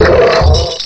cry_not_ferrothorn.aif